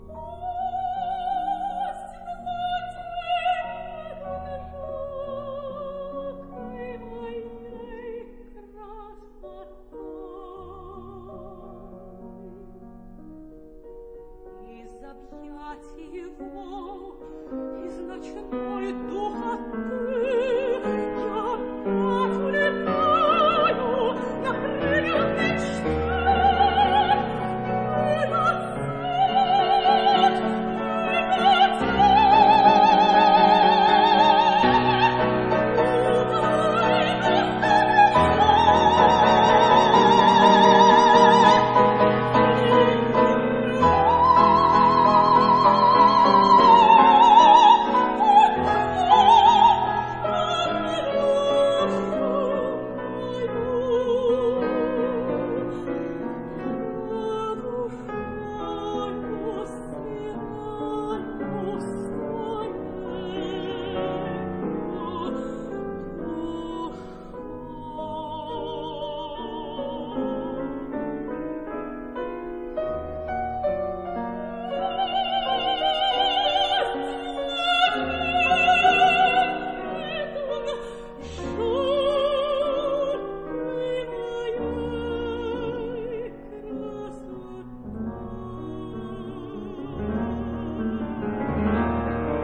試聽二，則是管弦樂版的開頭，試聽三是鋼琴版的開頭。
管弦樂版有者較正常的速度，
但是指揮處理銅管與弦樂部的配重、層次，幾乎可以搶戲了。